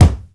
punch_low_deep_impact_05.wav